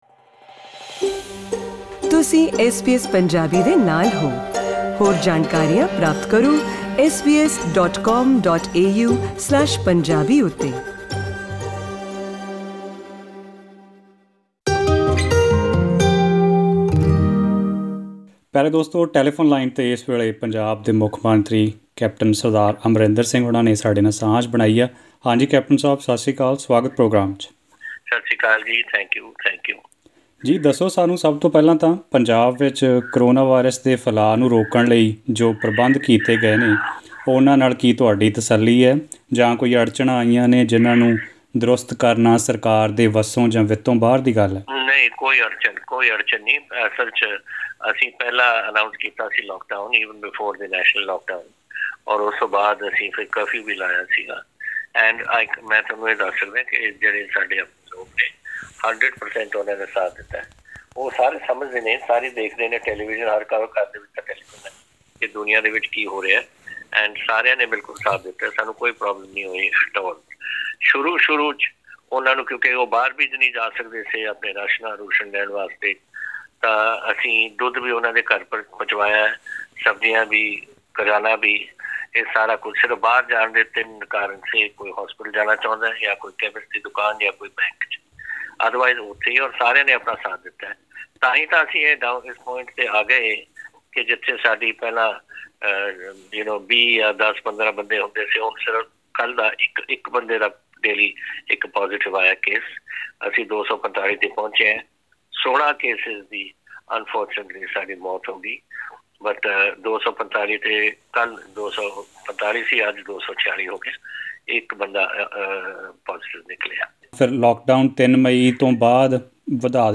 EXCLUSIVE INTERVIEW: Punjab Chief Minister Captain Amarinder Singh talks about state’s efforts to combat coronavirus